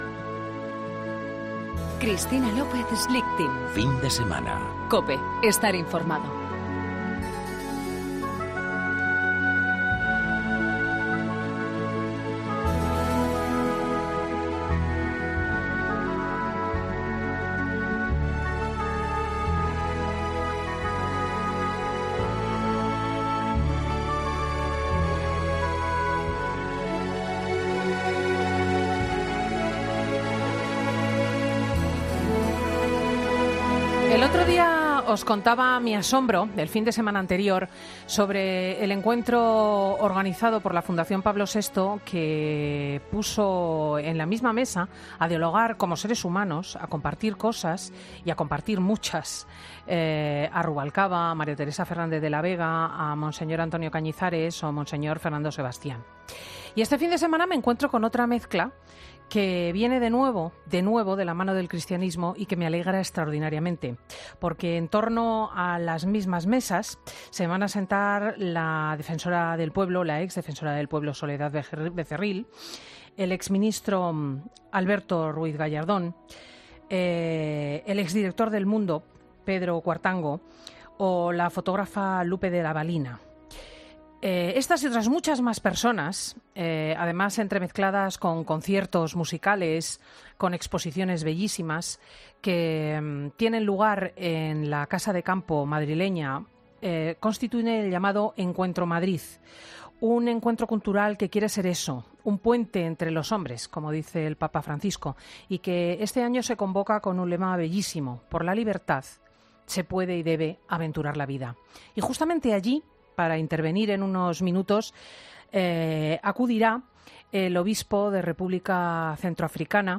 ESCUCHA LA ENTREVISTA COMPLETA A MONSEÑOR AGUIRRE EN 'FIN DE SEMANA' Pero esta vocación se pone a prueba todos los días , la realidad con la que vive Monseñor Aguirre es límite.